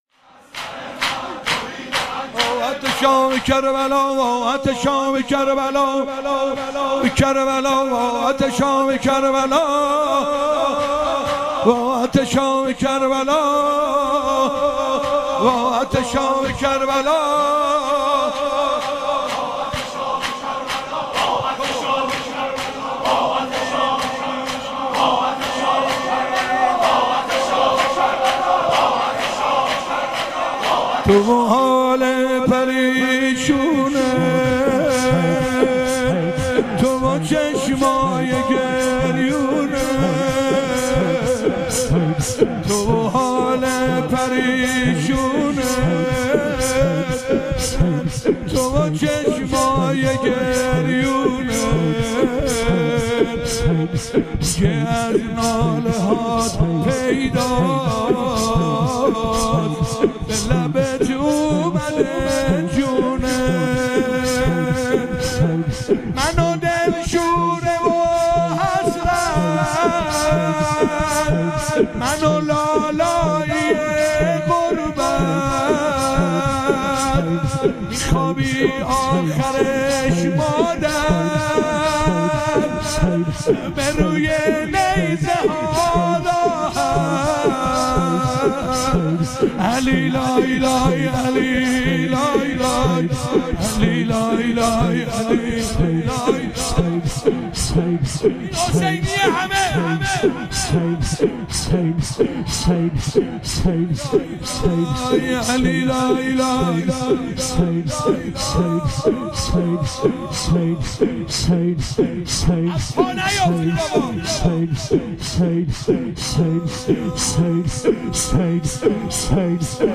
شور - وا عطشا به کربلا